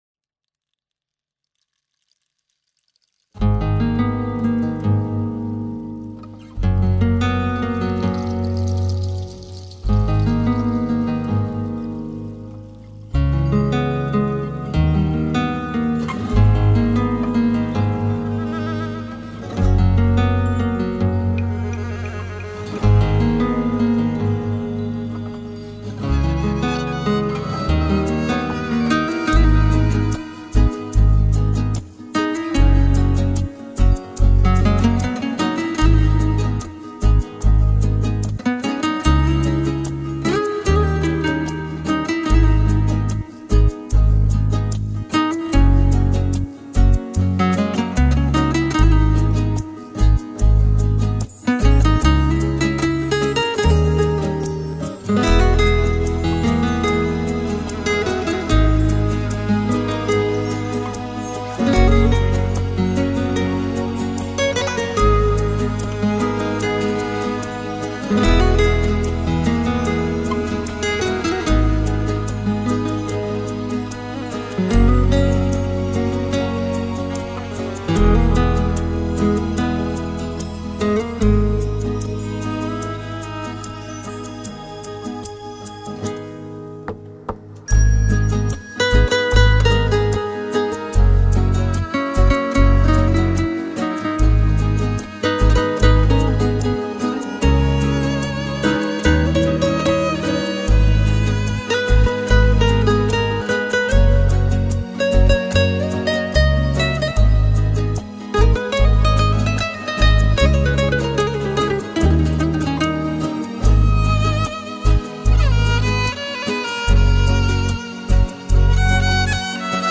一曲里展现浪漫至极的地中海风貌